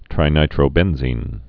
(trī-nītrō-bĕnzēn, -bĕn-zēn)